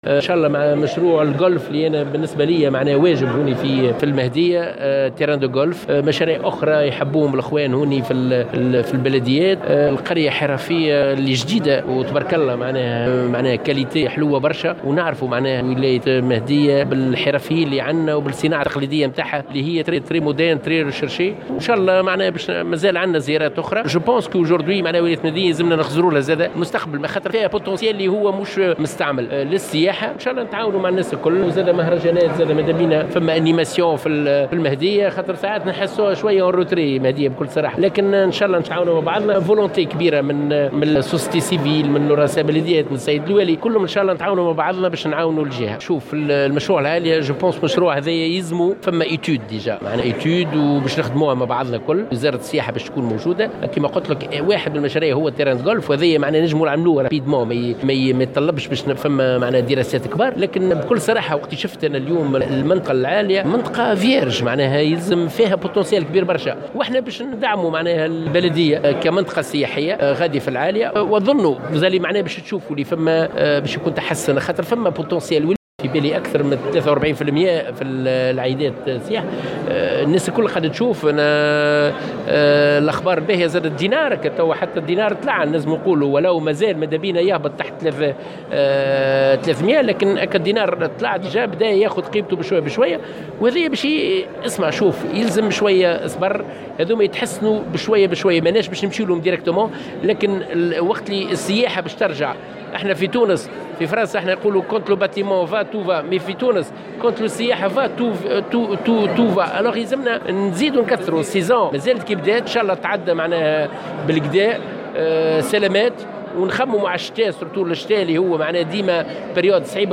وشدد الطرابلسي ، في تصريح لمراسل الجوهرة أف أم، على هامش زيارته الى ولاية المهدية، على ضرورة تنويع المنتوج السياحي الذي تقدمه تونس، عبر الاستثمار في اختصاصات متعددة كالسياحة البيئية والطبيعية والصناعات التقليدية، إضافة إلى دعم المستثمرين في مجال "منازل الإقامة" Maisons d'hote بما من شأنه أن يرفع من قدرة تونس على استقطاب السياح خاصة في فترات الذروة.